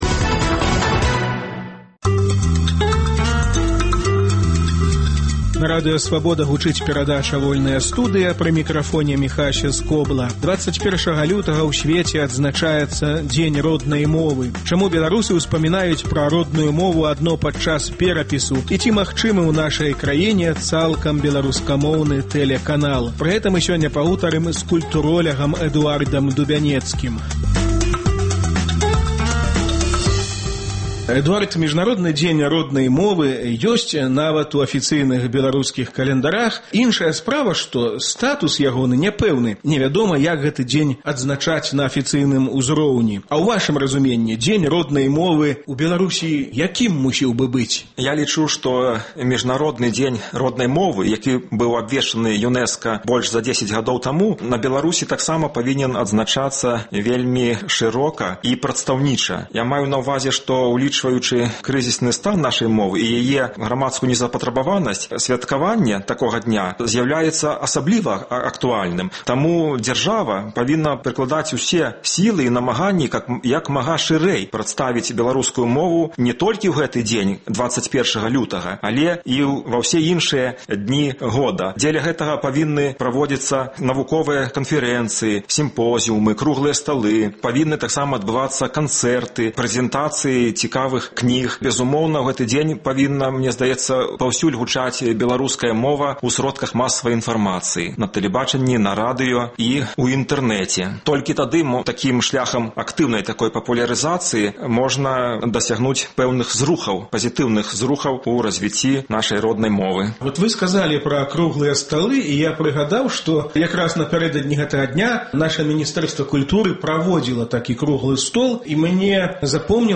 Пра гэта ды іншае – у гутарцы